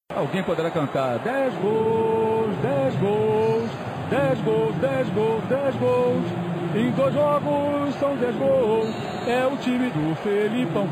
mauro-cezar-pereira-cantando-10-gols_yI4Z63X.mp3